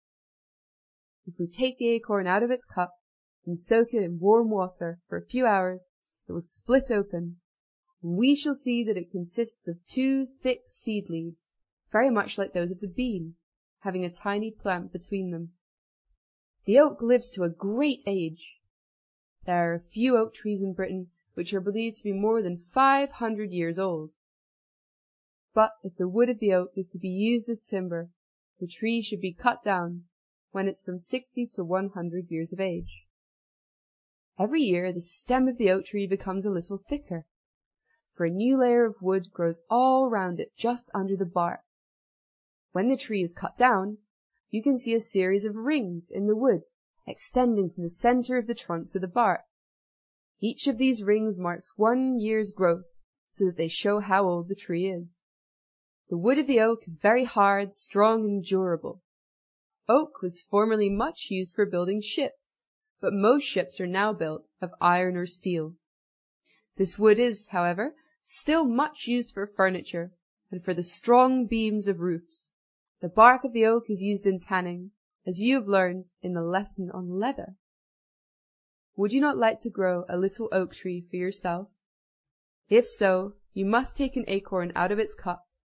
在线英语听力室英国学生科学读本 第90期:结实的橡树(2)的听力文件下载,《英国学生科学读本》讲述大自然中的动物、植物等广博的科学知识，犹如一部万物简史。在线英语听力室提供配套英文朗读与双语字幕，帮助读者全面提升英语阅读水平。